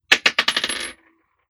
Bouncing Bullet 005.wav